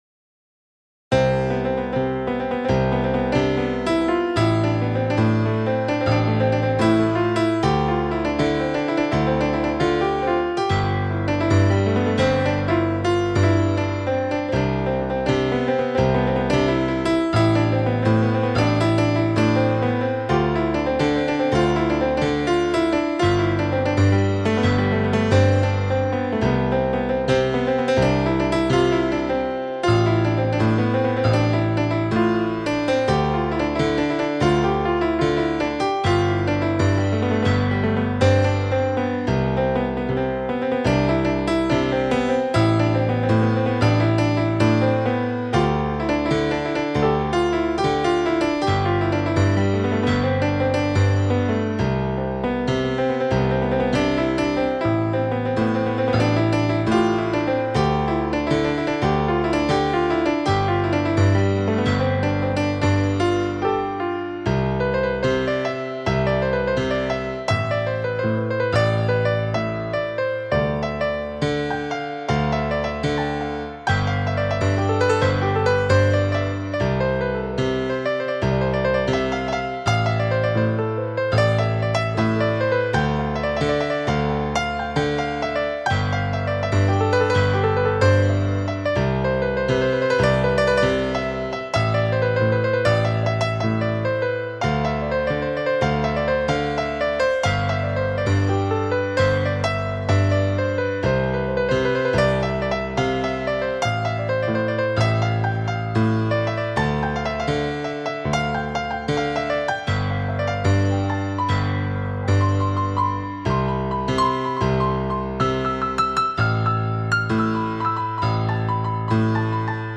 Piano Keys